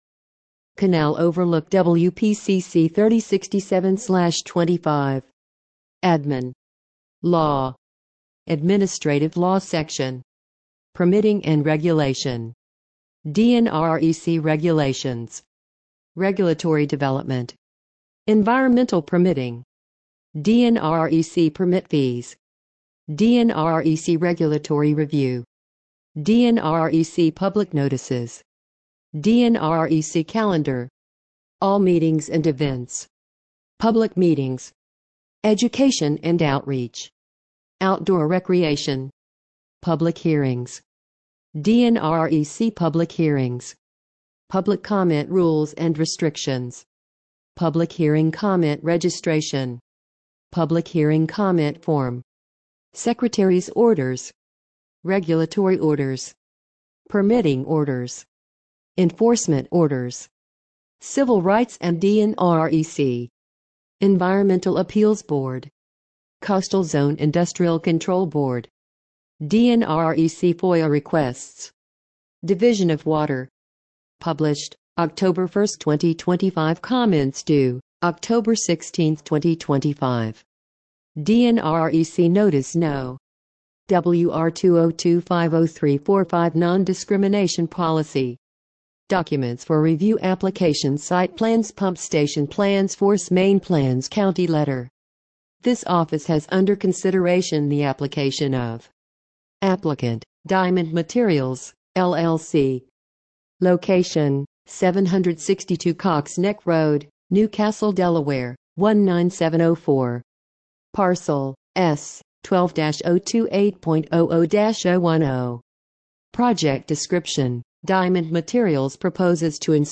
Listen to this page using ReadSpeaker